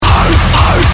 Play, download and share Arf Arf original sound button!!!!
arfarf.mp3